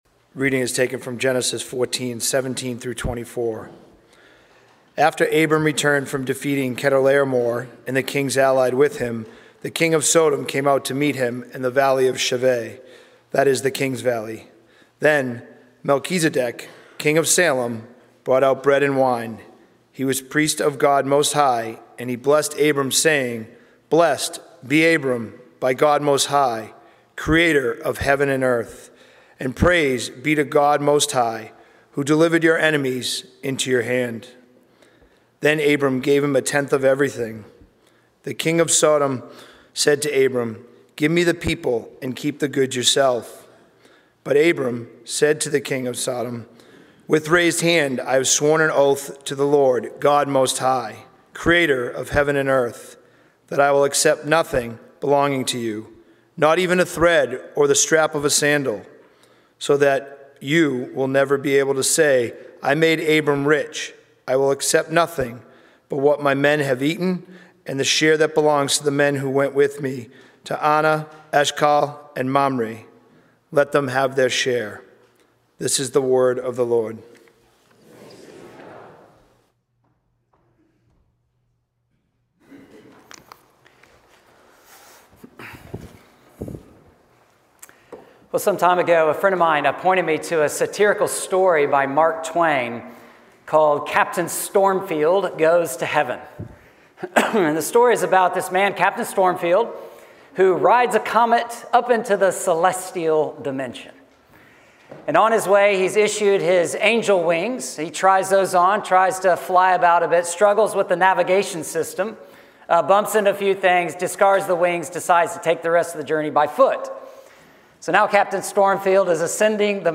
Sermons – North Shore Community Baptist Church